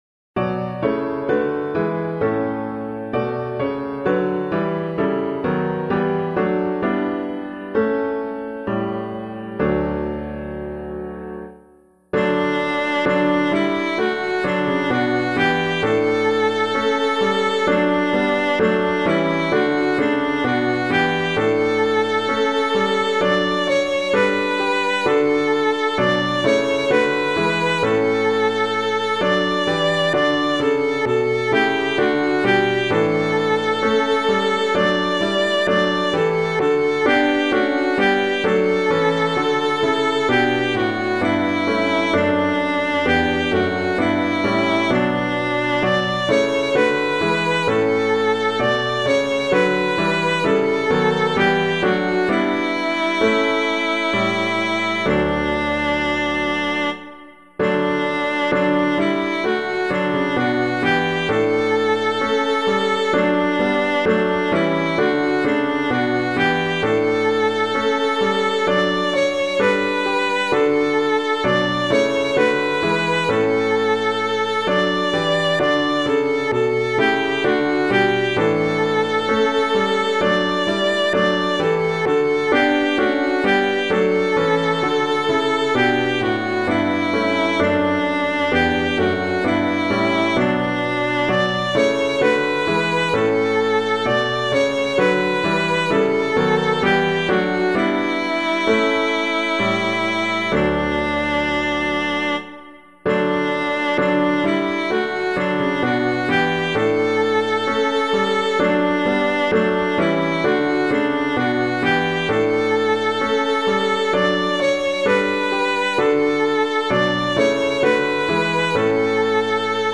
Public domain hymn suitable for Catholic liturgy.
Jesus Is Risen Let Us Sing [anonymous - LASST UNS ERFREUEN] - piano.mp3